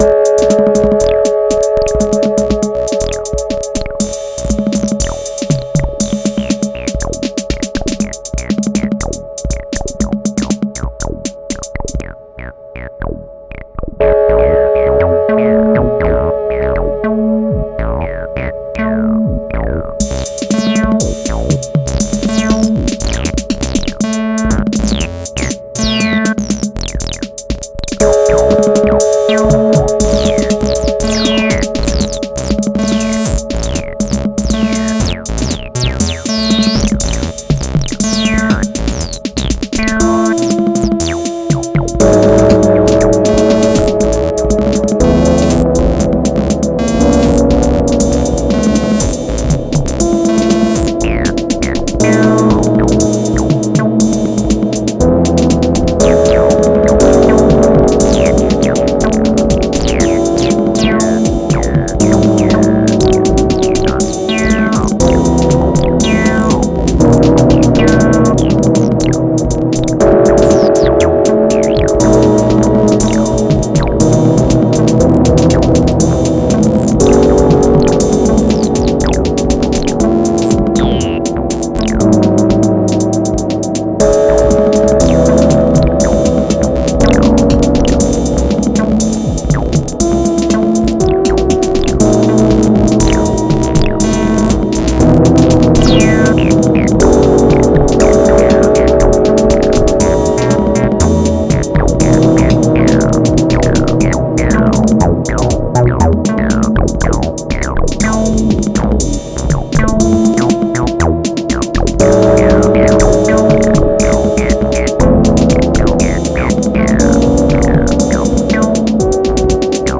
Genre Mellow